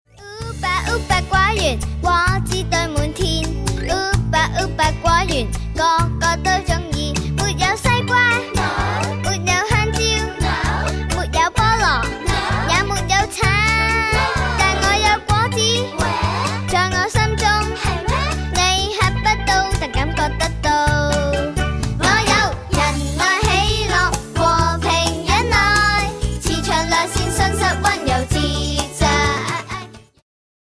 充滿動感和時代感